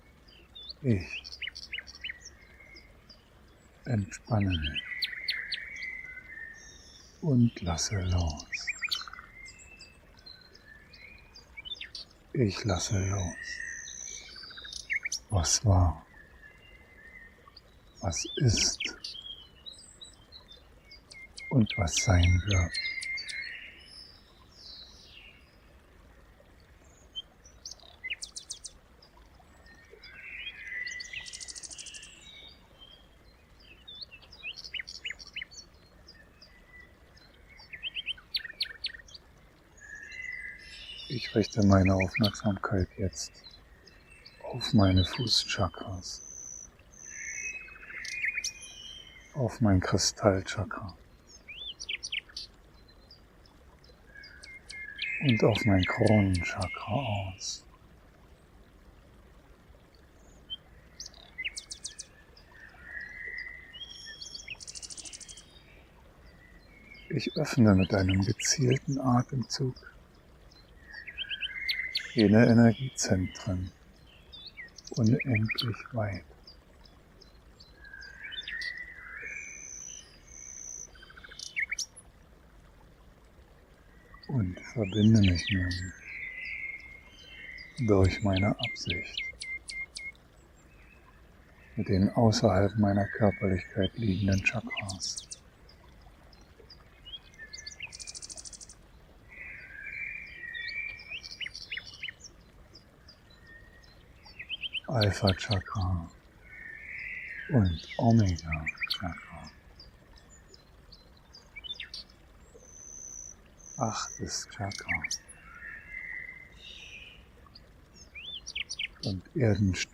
erd-herz-quellatmung-in-der-natur.mp3